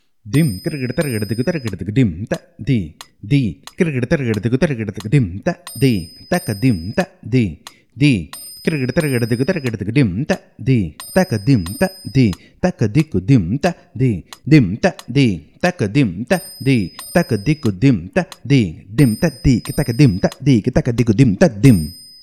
This is a mukthayam of 32 beats, which is a combination of both chaturashra nade and trishra nade.
Konnakol